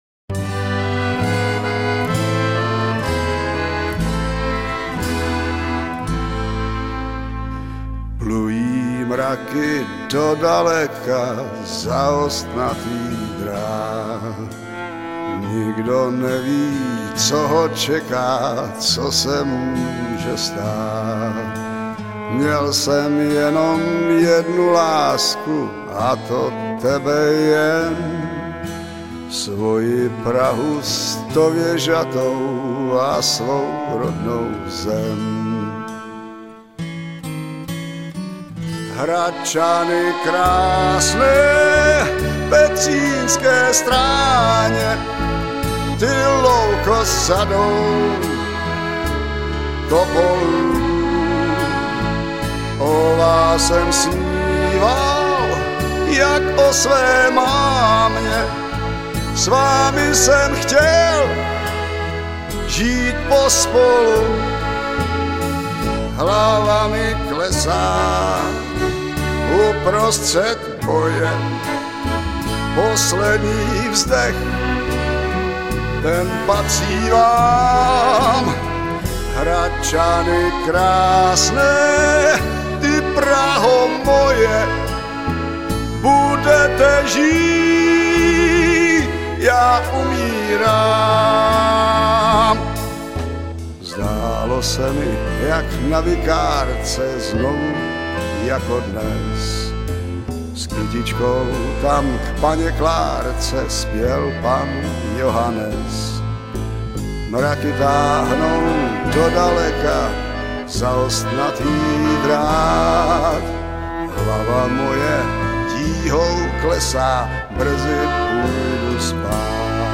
hrají a zpívají